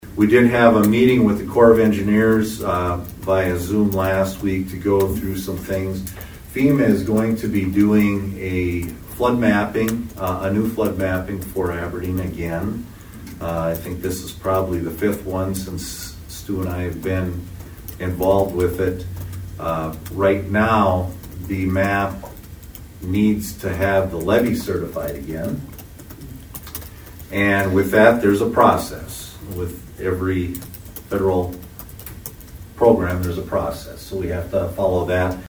ABERDEEN, S.D.(HubCityRadio)- Toward the end of Monday’s City Council meeting, Aberdeen City Manager Robin Bobzien addressed couple issues dealing with the city of Aberdeen.